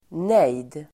Ladda ner uttalet
Uttal: [nej:d]